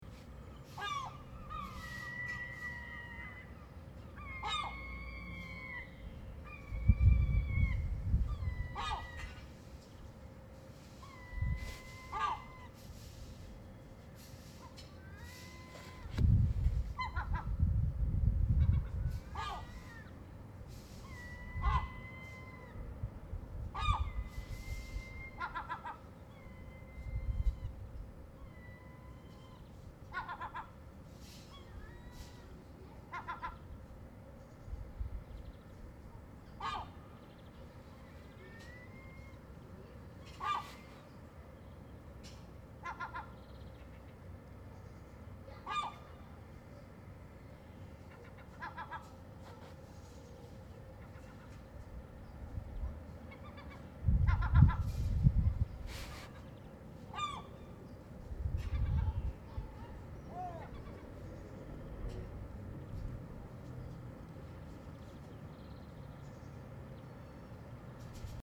08/03/2015 10:00 Le centre ville de Leiden est calme et charmant.
De grandes cathédrales surgissent de l’ensemble, et le carillon sonne. Installés à la terrasse d’un grand cube en verre, nous buvons un thé devant le canal principal.